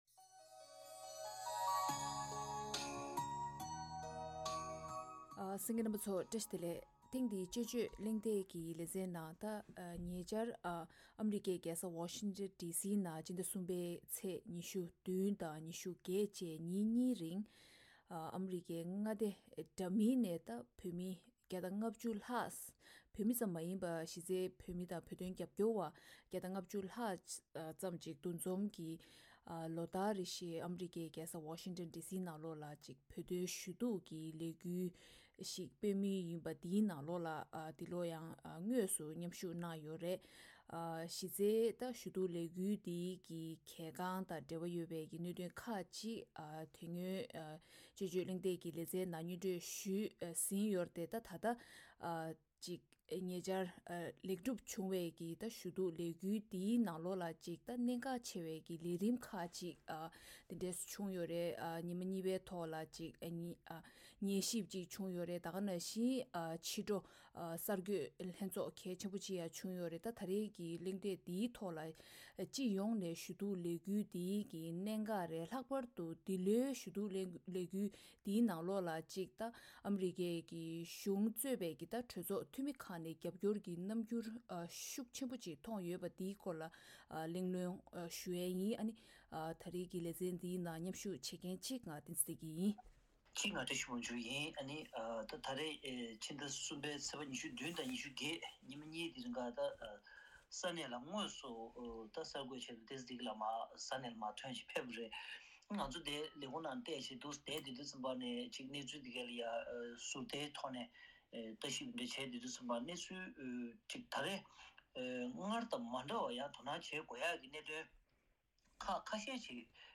ཐེངས་འདིའི་དཔྱད་བརྗོད་གླེང་སྟེགས་ཀྱི་ལས་རིམ་ནང་གྲོས་བསྡུར་ཞུ་རྒྱུ་དང་།